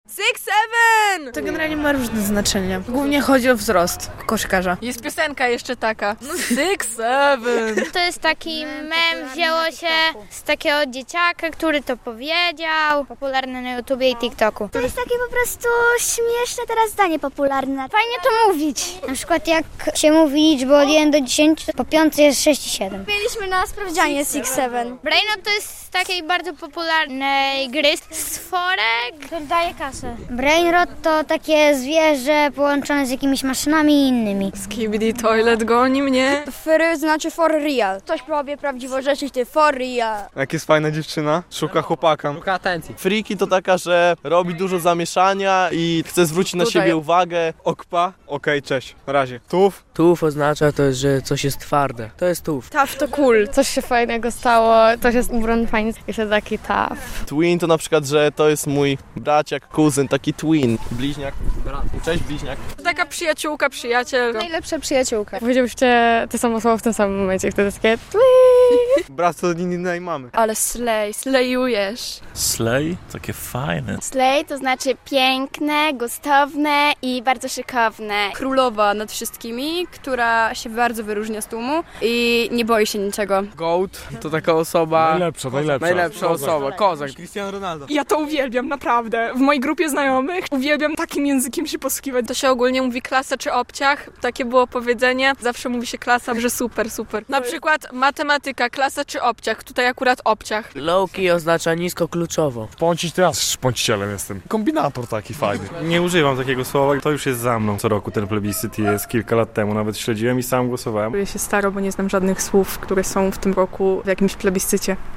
Młodzi białostoczanie tłumaczą swój slang - relacja